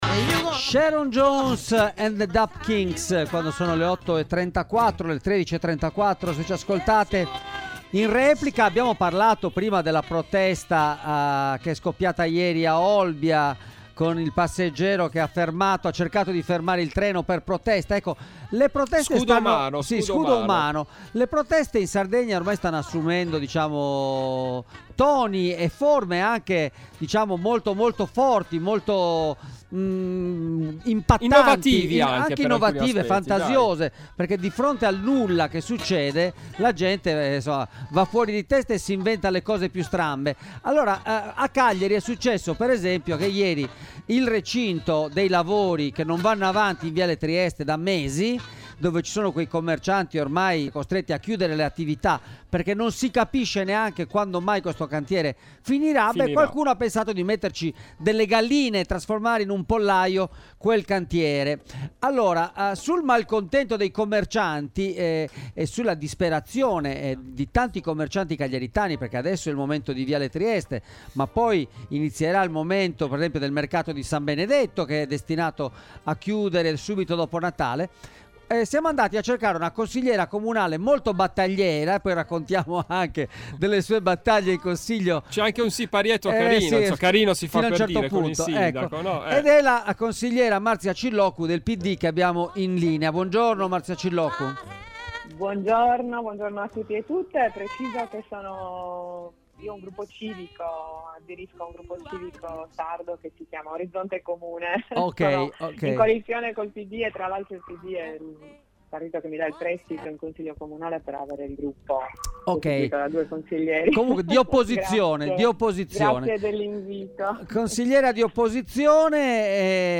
Ne abbiamo parlato all’interno di Extralive mattina in collegamento con Marzia Cilloccu, ex assessora al turismo, attività produttive e pari opportunità del Comune di Cagliari, oggi consigliera di opposizione, che sin dall’av